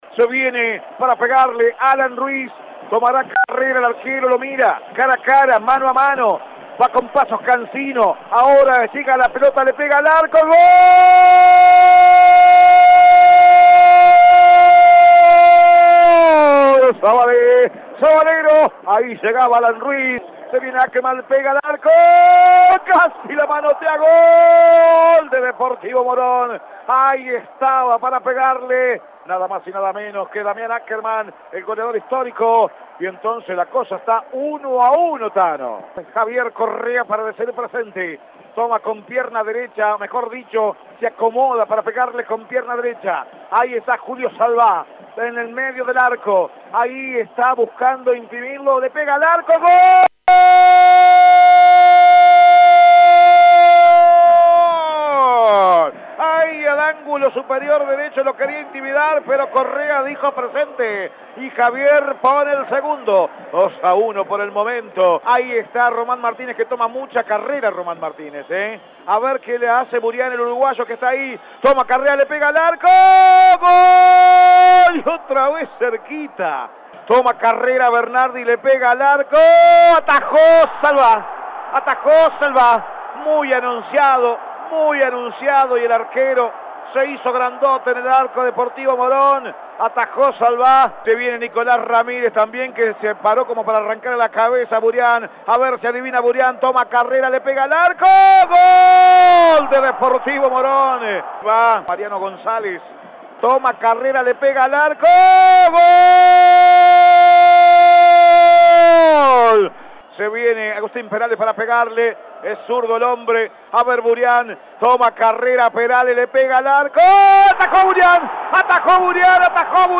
Definición por penales- Radio EME 96.3